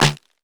SNARE [Timbo].wav